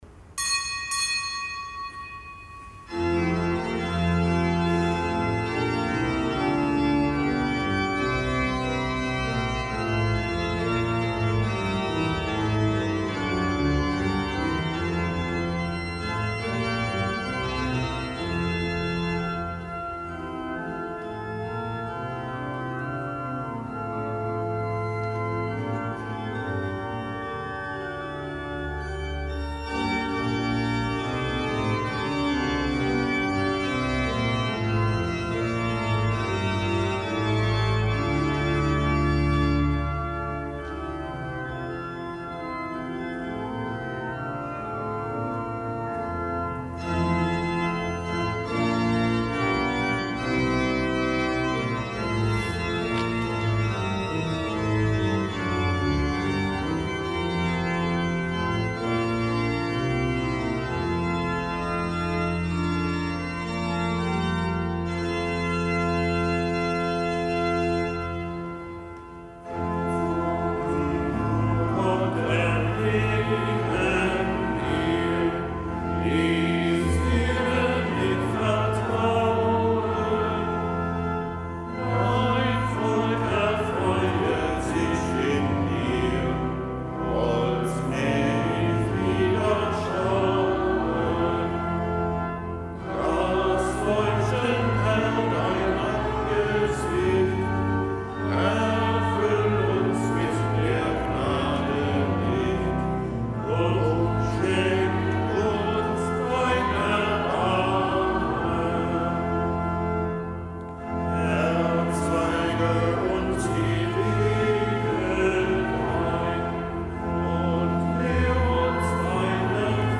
Kapitelsmesse aus dem Kölner Dom am Montag der vierunddreißigsten Woche im Jahreskreis, Nichtgebotener Gedenktag Heiligen Katharina von Alexandrien, Jungfrau, Märtyrin.
Zelebrant: Weihbischof Dominikus Schwaderlapp.